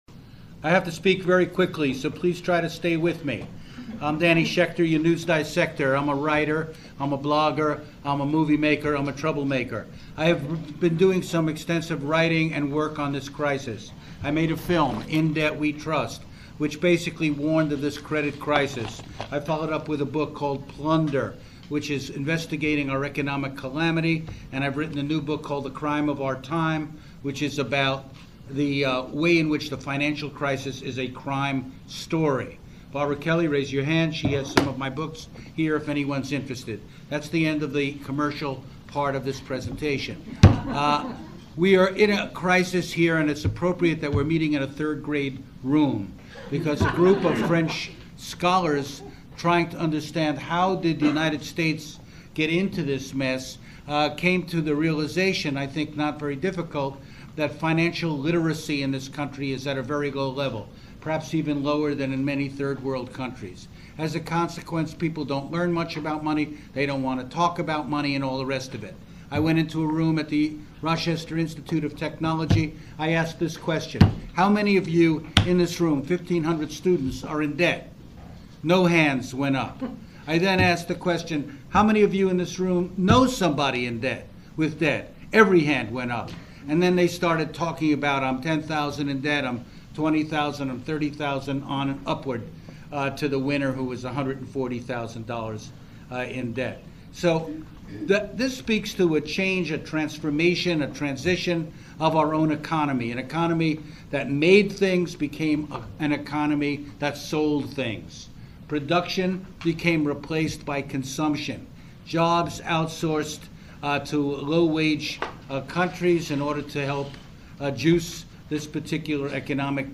We’ll bring you a recording of Noam Chomsky’s October 2nd appearance in Portland, as well as other featured speakers from the EconVergence.